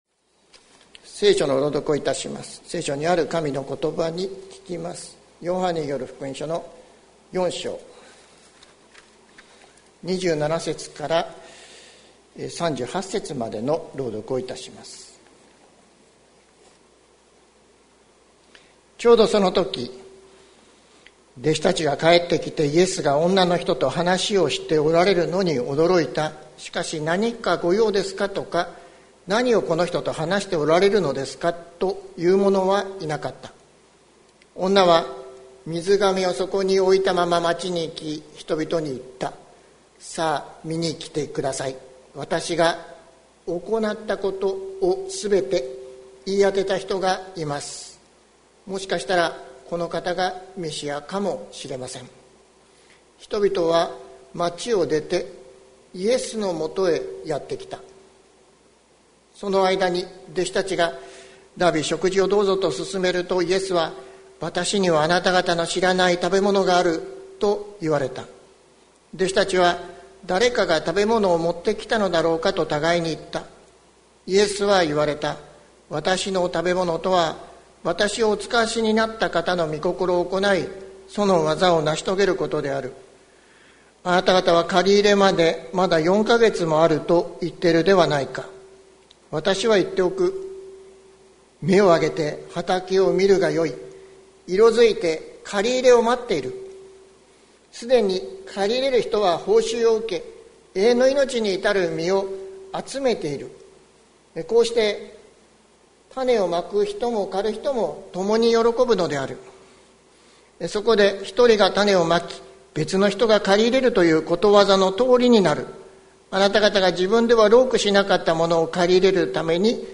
2022年02月13日朝の礼拝「あたらしく見よう」関キリスト教会
説教アーカイブ。